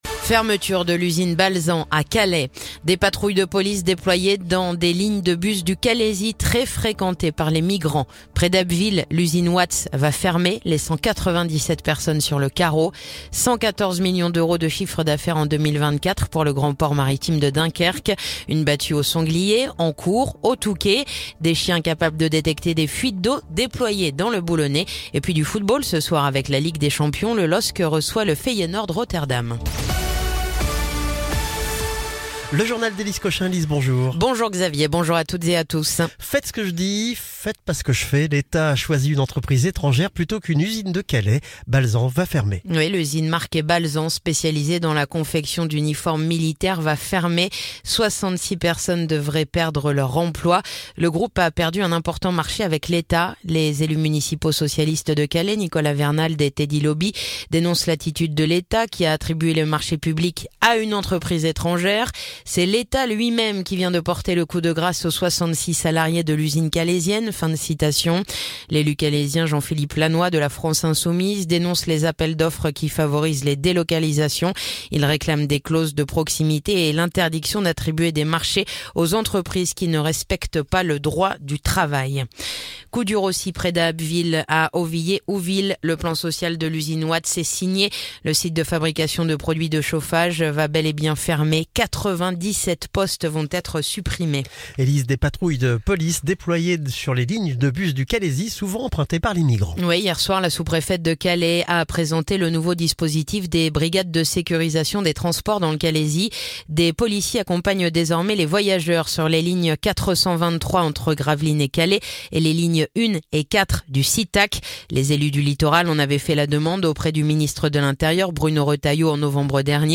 Le journal du mercredi 29 janvier